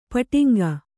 ♪ paṭiŋga